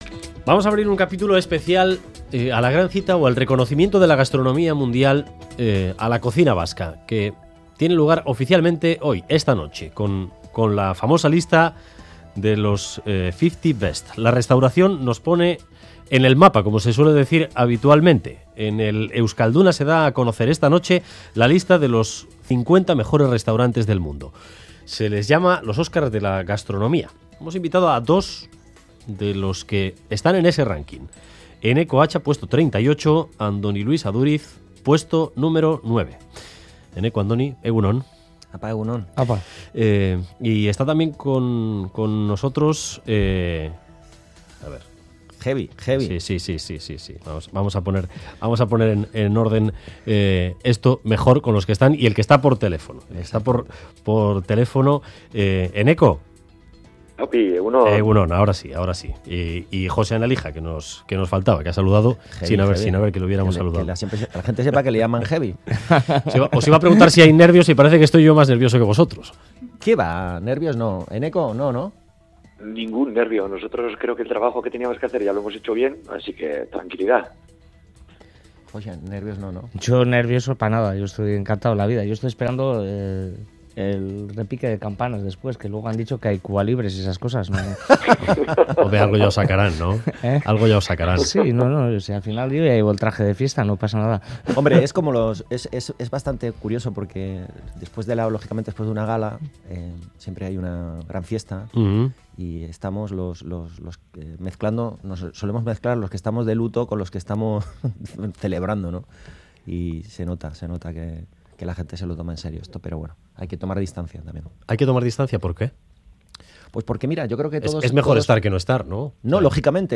Hemos hablado con Eneko Atxa, Andoni Luis Aduriz y Josean Alija sobre 'The World's 50 Best Restaurants' y han subrayado que están "encantados de la vida".